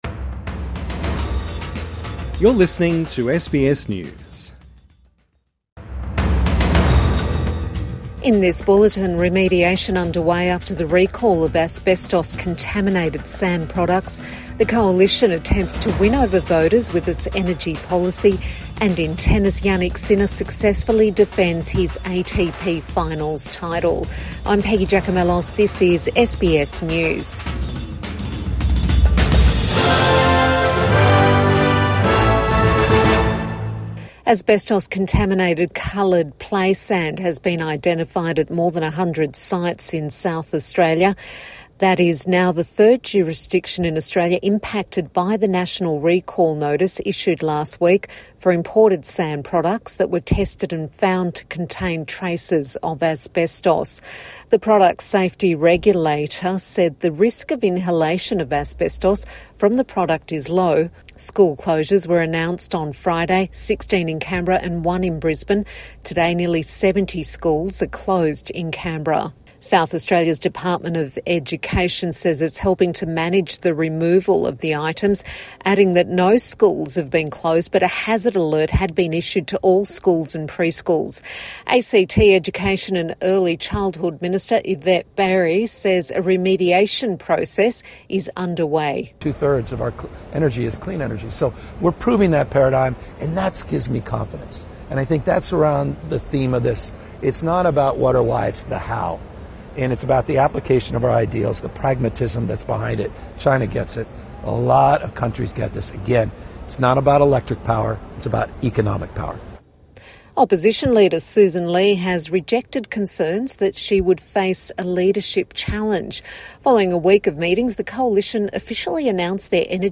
More schools found to have asbestos-contaminated sand products | Midday News Bulletin 17 November 2025